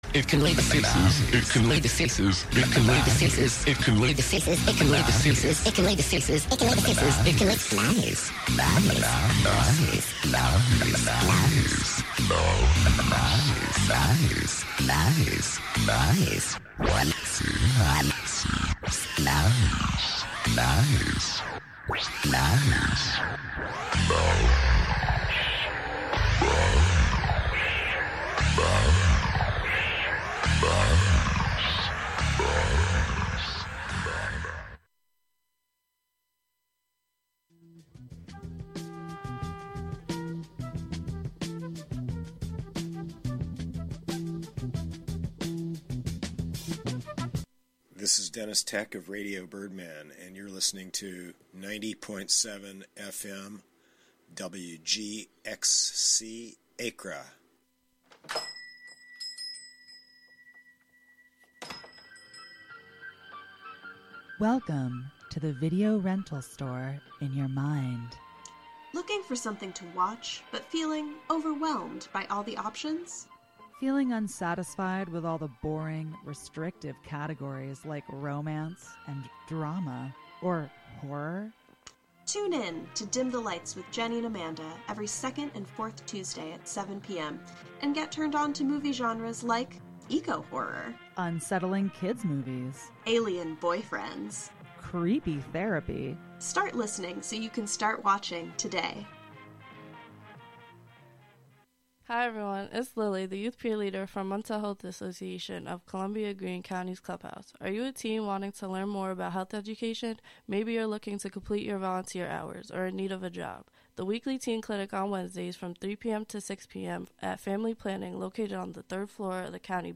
listen to and discuss each other's favorite songs and singers, take requests from callers, give advice, and even cast a spell or two